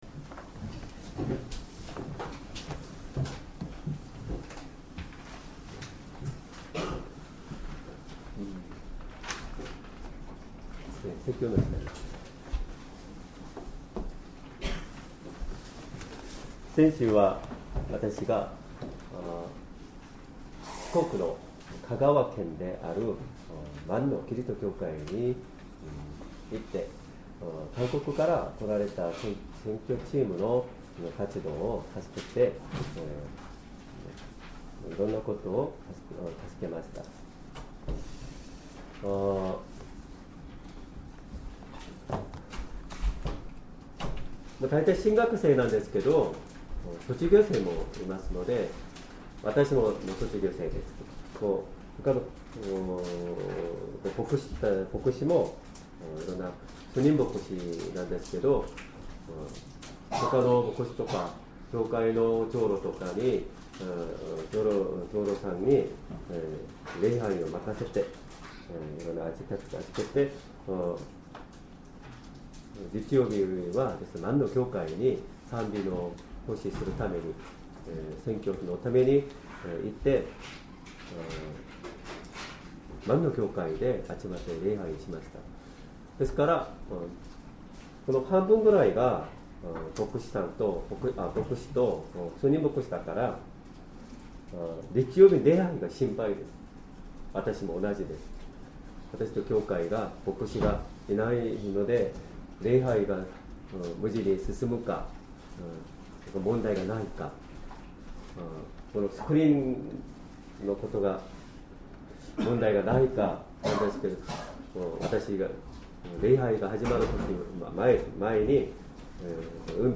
Sermon
2025年2月9日 主日礼拝 説教 「弱い時にこそ、強くなる」 聖書 コリント人への手紙 Ⅱ 12:9-10 12:9 しかし主は、「わたしの恵みはあなたに十分である。